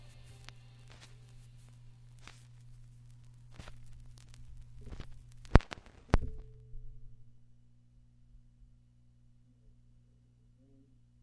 黑胶唱片噪音和脉冲响应2 " surfacecrvnoisewithIR
描述：通过USB录制的数字化黑胶唱片的片段。
Tag: 脉冲响应 葡萄酒 LP 乙烯基 转盘 记录 表面噪声